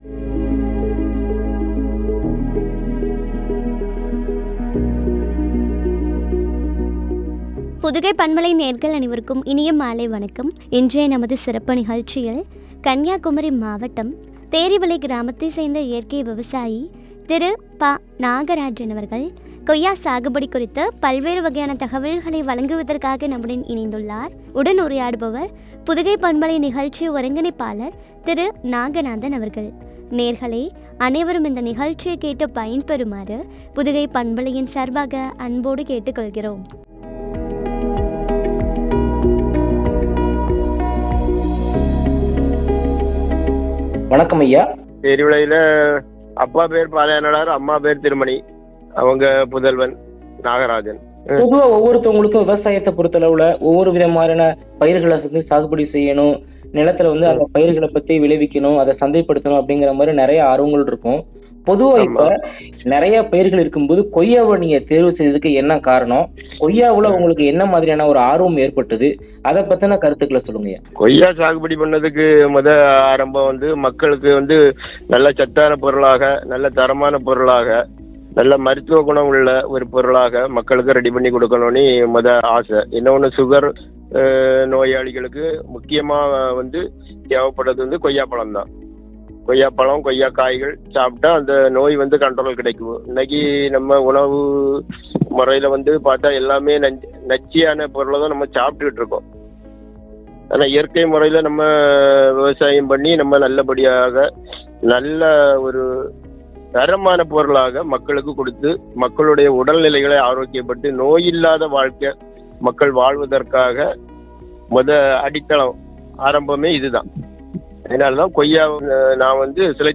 கொய்யா சாகுபடியில் மகிழும் விவசாயி குறித்து வழங்கிய உரையாடல்.